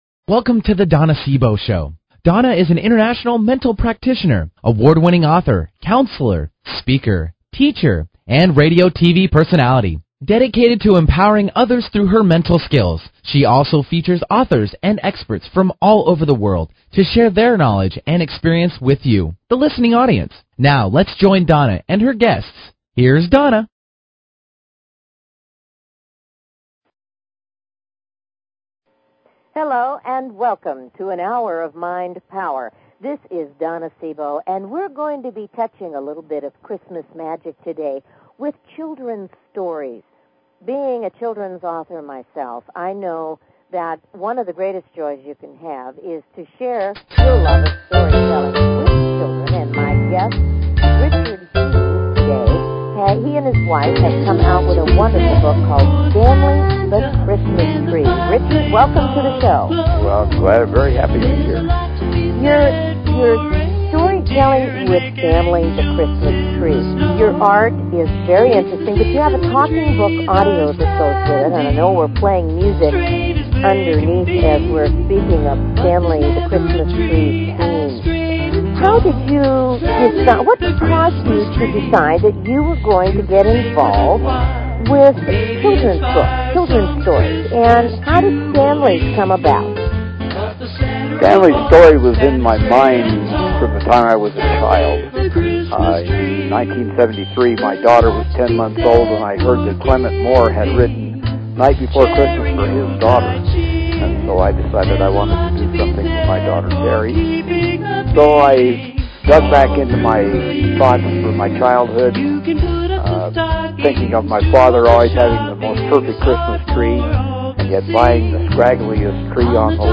Her interviews embody a golden voice that shines with passion, purpose, sincerity and humor.
Talk Show
Callers are welcome to call in for a live on air psychic reading during the second half hour of each show.